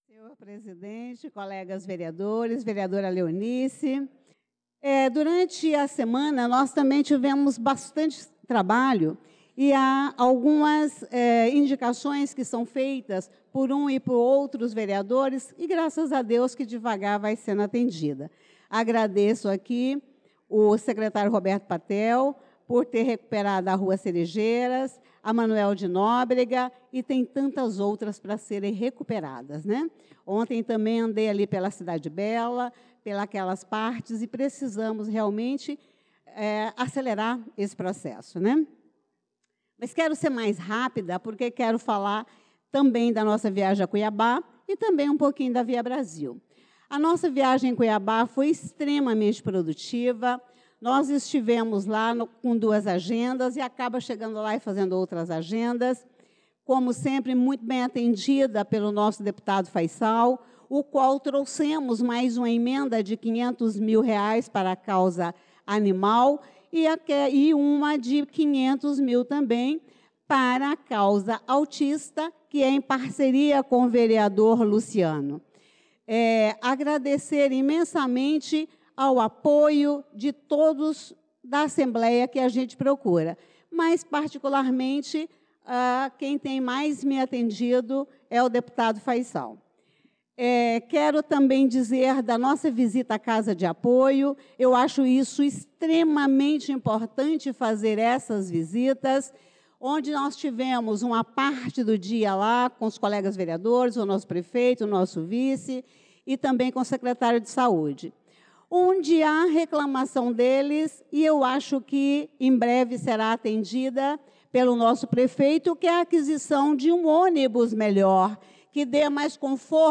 Pronunciamento da Vereadora Elisa Gomes na Sessão Ordinária do dia 25/03/2025